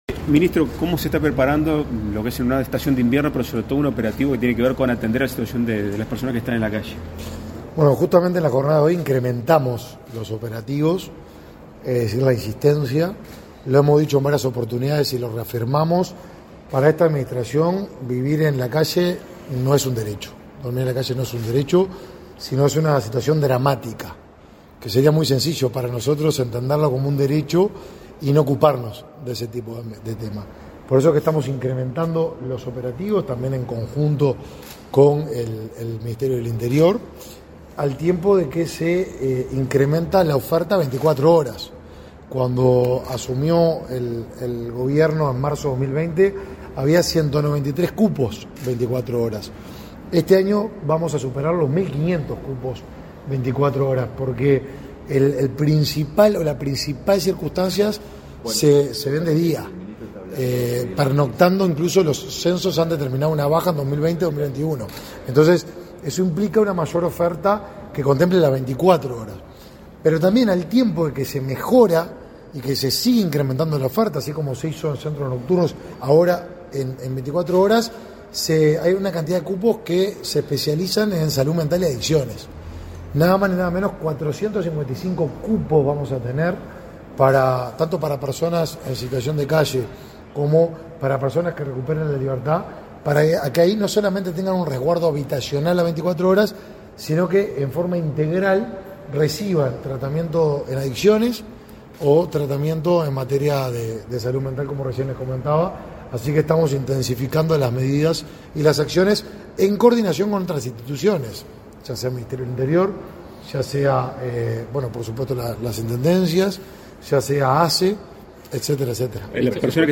Declaraciones a la prensa del ministro de Desarrollo Social, Martín Lema
Declaraciones a la prensa del ministro de Desarrollo Social, Martín Lema 10/04/2023 Compartir Facebook X Copiar enlace WhatsApp LinkedIn Tras participar en la reunión de acuerdos con el presidente de la República, Luis Lacalle Pou, este 10 de abril, el ministro de Desarrollo Social, Martín Lema, realizó declaraciones a la prensa.